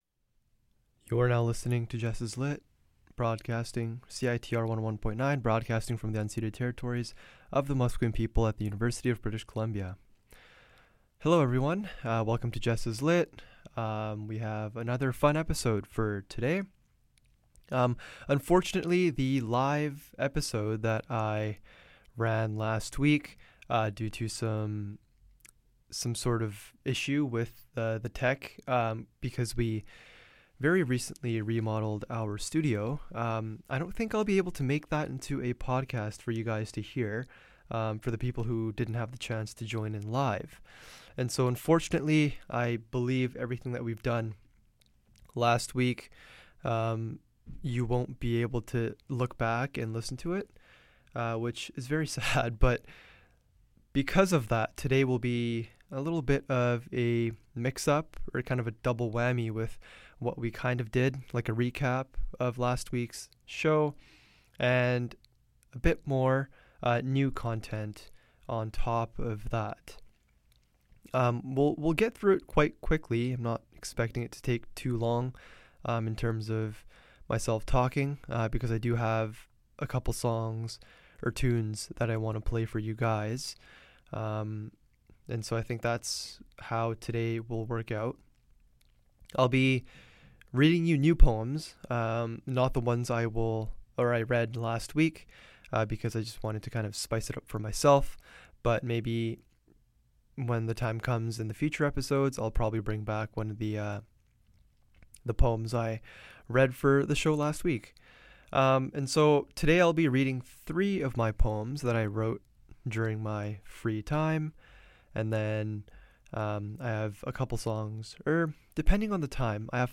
In this episode I read my personal poems out loud and give an analysis on them. In between the poetry readings I will play some tunes that will relax your brain.